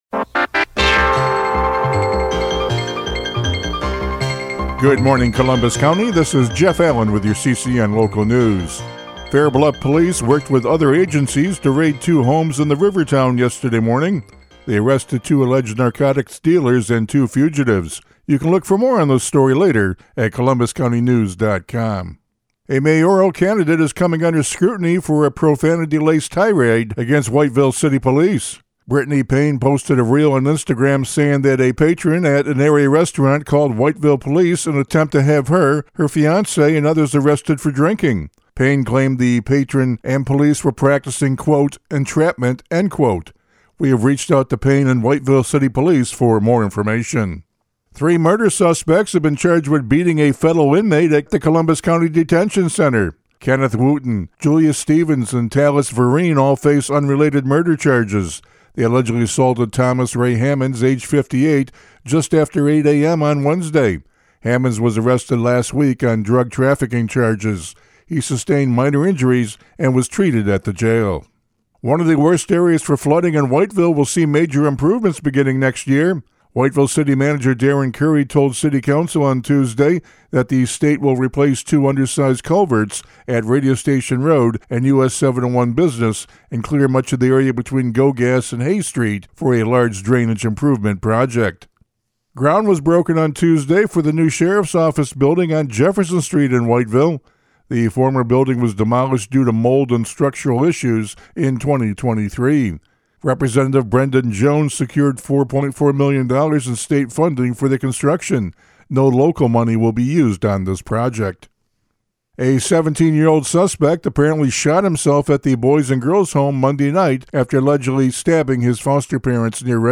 CCN Radio News — Morning Report for October 31, 2025